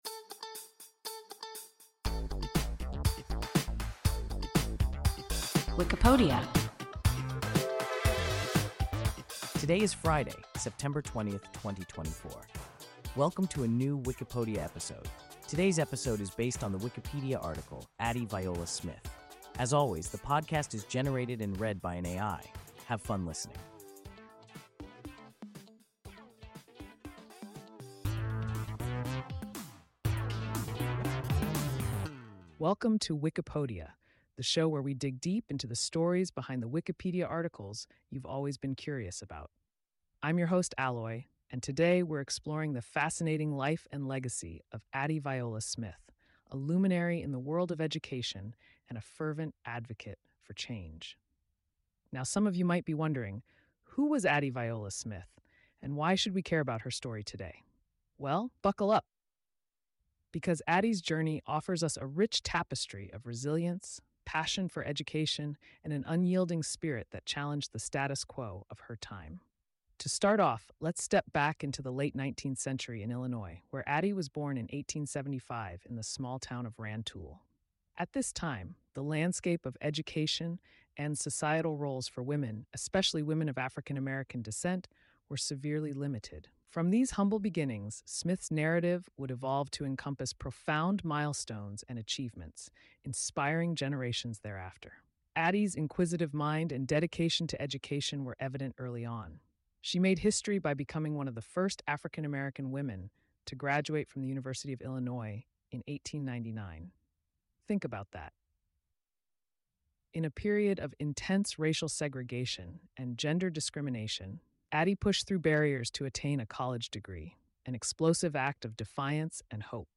Addie Viola Smith – WIKIPODIA – ein KI Podcast
Wikipodia – an AI podcast